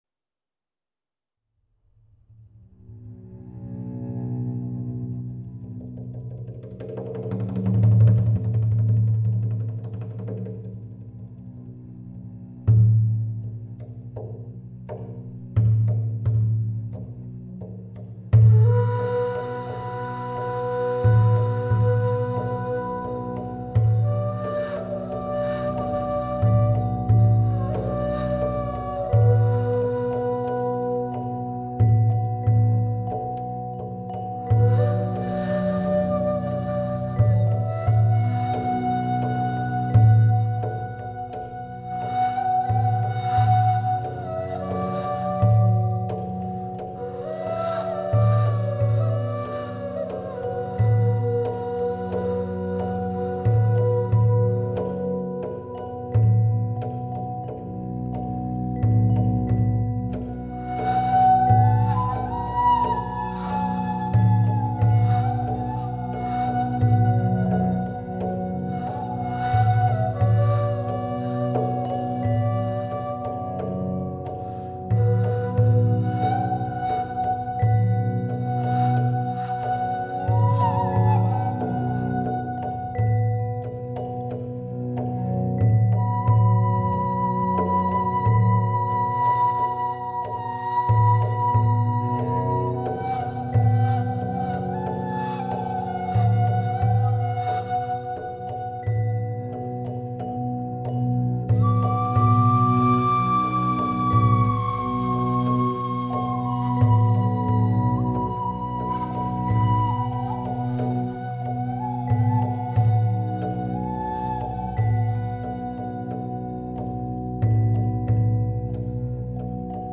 Wortgottesdienst zum Thema:
Meditation
Ruprechtskirche Wien